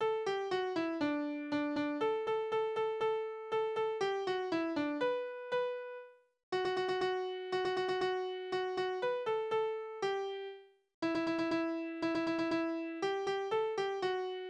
Tonart: D-Dur Taktart: 4/8 Tonumfang: große Sexte Besetzung: vokal